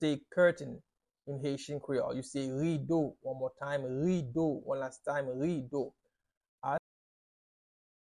Pronunciation:
Listen to and watch “Rido” pronunciation in Haitian Creole by a native Haitian  in the video below:
How-to-say-Curtain-in-Haitian-Creole-Rido-pronunciation-by-a-Haitian-Creole-teacher.mp3